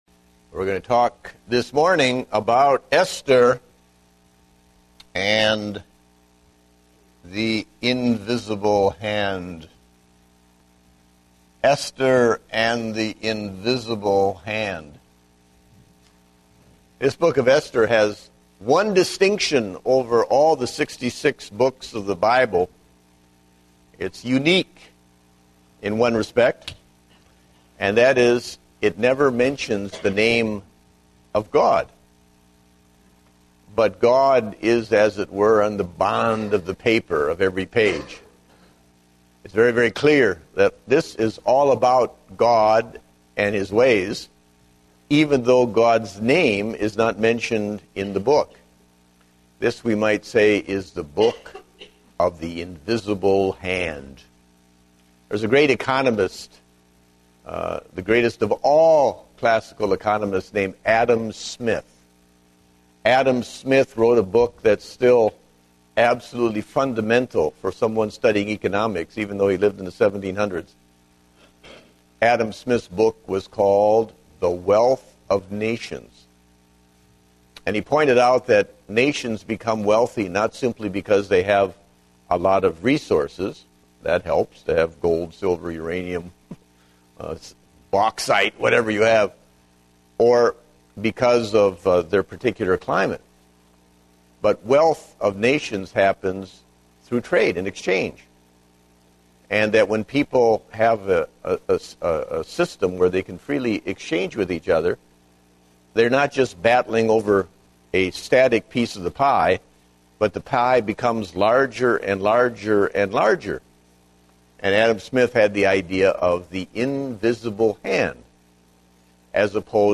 Date: June 13, 2010 (Adult Sunday School)